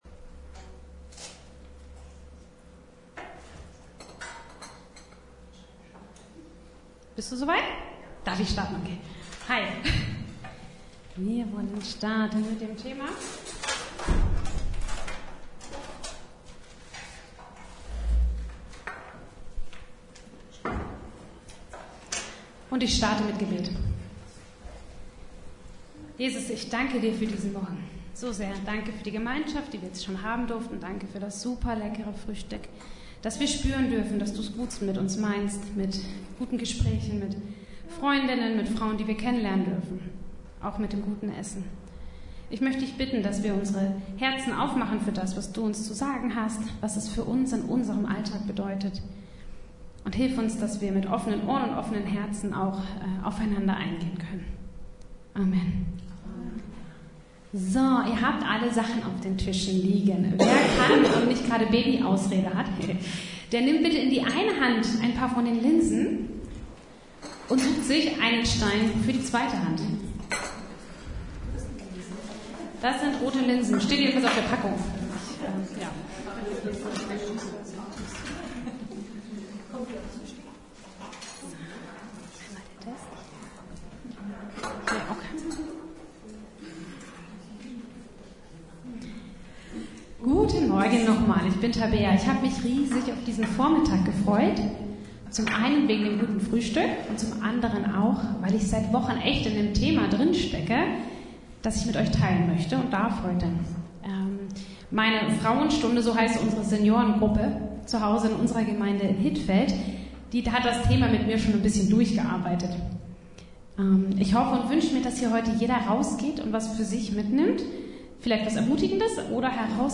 Rut 1 Kategorie: Vorträge « Der Christ und das alttestamentliche Gesetz